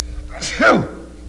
Sneeze Sound Effect
Download a high-quality sneeze sound effect.
sneeze.mp3